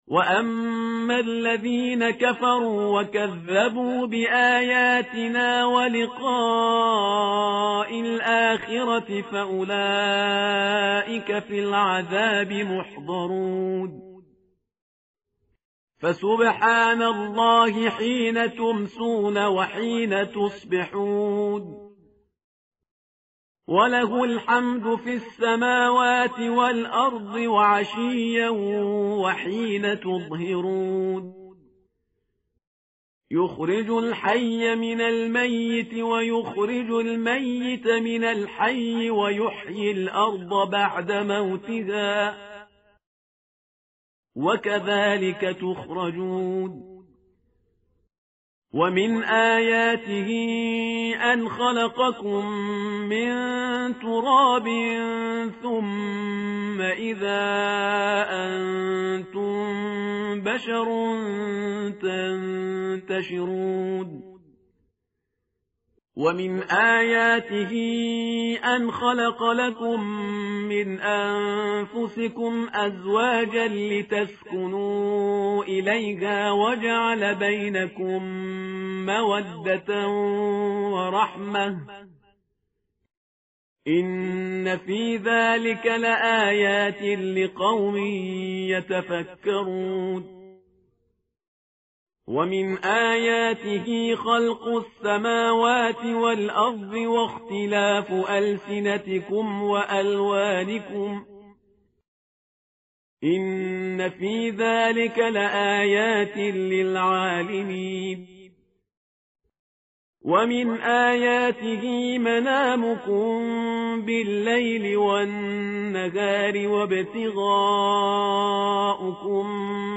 متن قرآن همراه باتلاوت قرآن و ترجمه
tartil_parhizgar_page_406.mp3